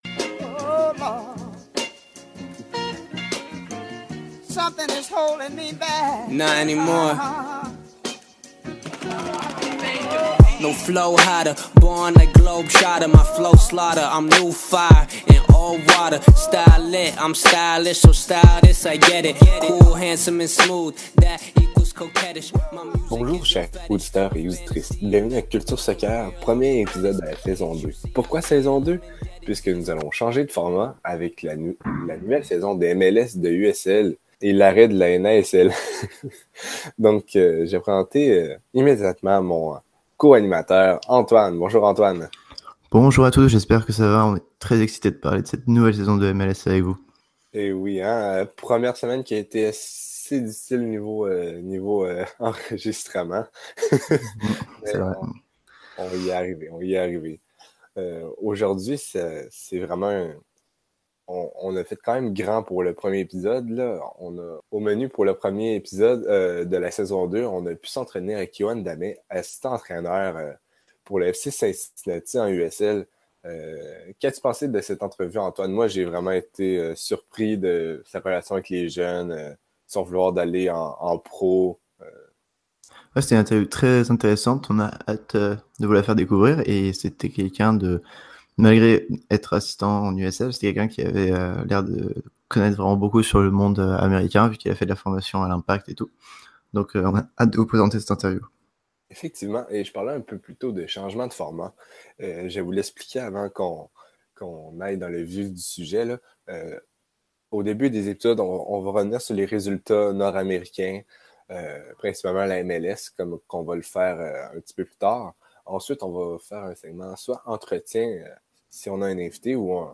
La MLS 2018 a débuté alors Culture Soccer passe en saison 2. Au menu de la nouvelle saison, un nouveau format avec un retour sur les faits marquants sur le terrain, un entretien exclusif et toujours du culture foot au pays du soccer.